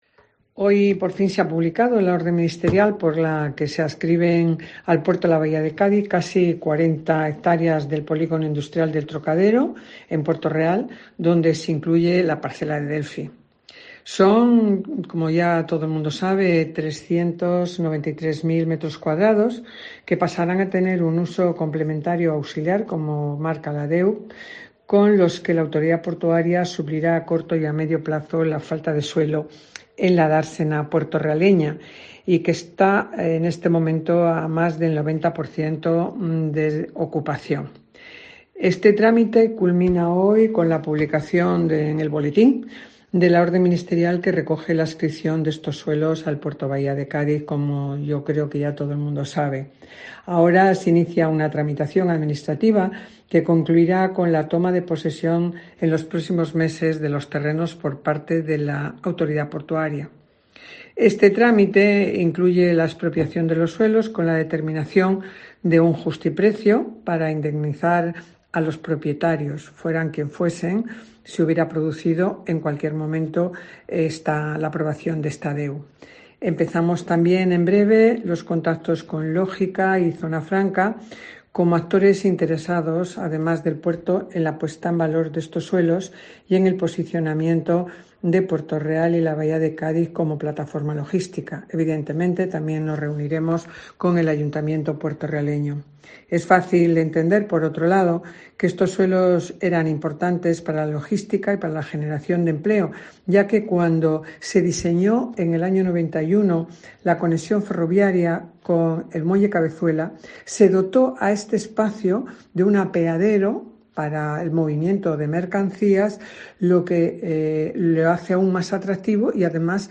Palabras de Teófila Martínez, presidenta de la Autoridad Portuaria de la Bahía de Cádiz